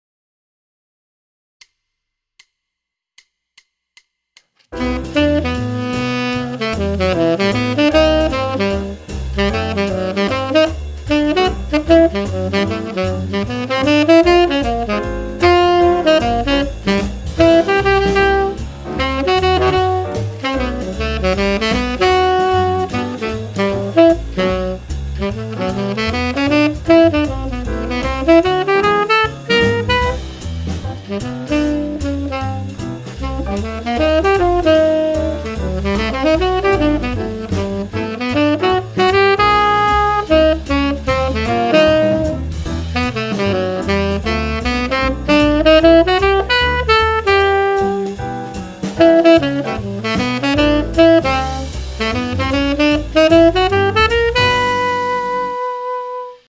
this is a practice-with-me track of all of 2019 TMEA All-State Jazz Guitar Etude 1, bpm=130. played on tenor saxophone using the iReal Pro app. mixed with GarageBand.